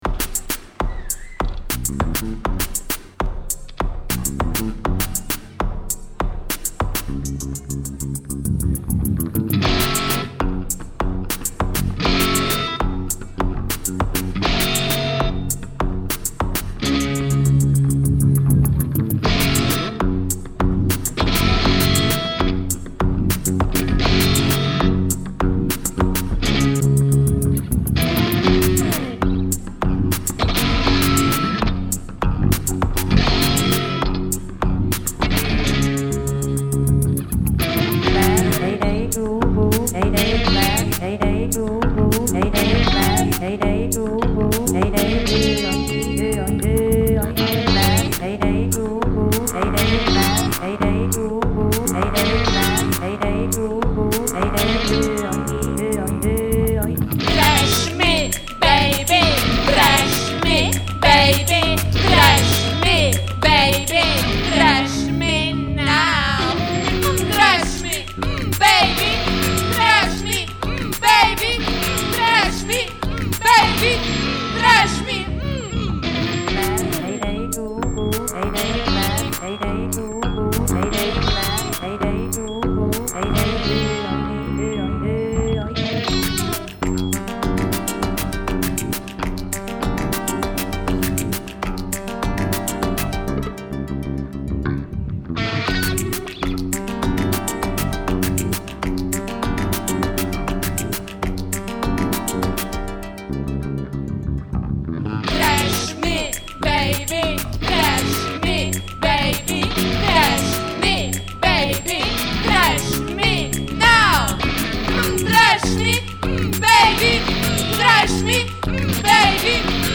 Godinama rade kao elektronsko-akustični sistem i DJ tim